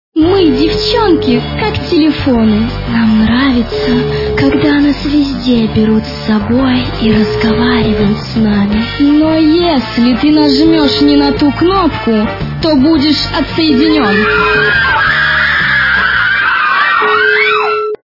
» Звуки » Смешные » Мы девченки, как телефоны. - Нам нарвится когда нас везде берут с собой. Но если ты нажмешь не на ту кнопку-будешь отсоединен!
При прослушивании Мы девченки, как телефоны. - Нам нарвится когда нас везде берут с собой. Но если ты нажмешь не на ту кнопку-будешь отсоединен! качество понижено и присутствуют гудки.